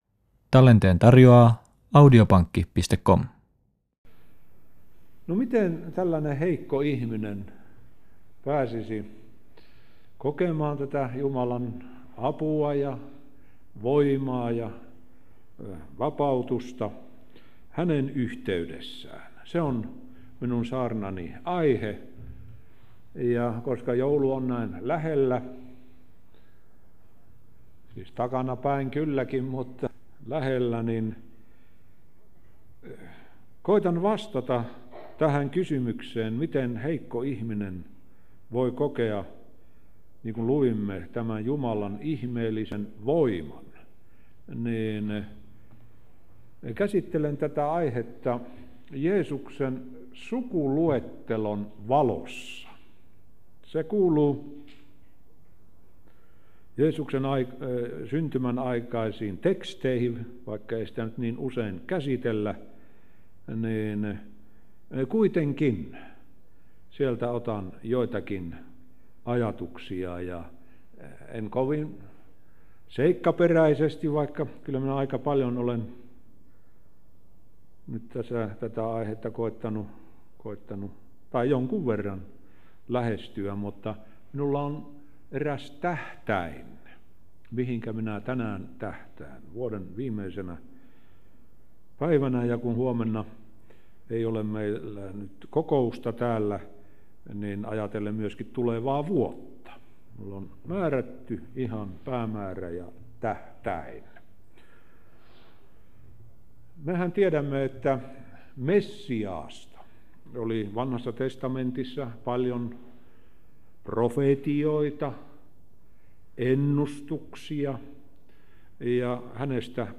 Aikamme on rajoitettua ja siksi sen käytön suunnittelu parantaa elämän laatua. Ehtoollispuhe.